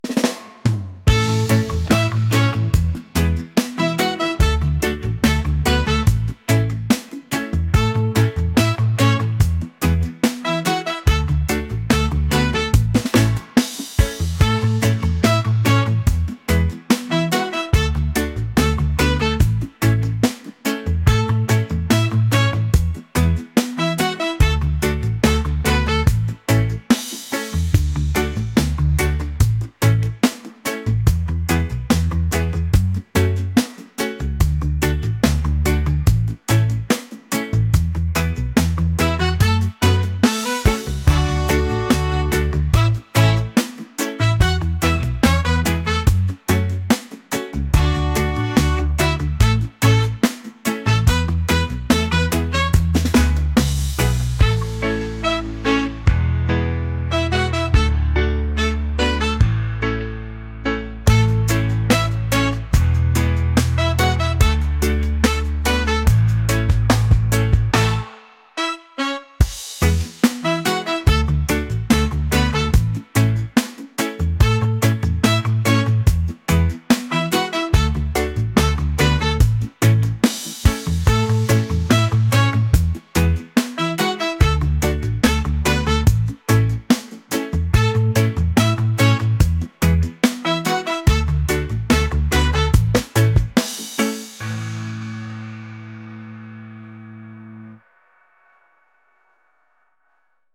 reggae | latin | lofi & chill beats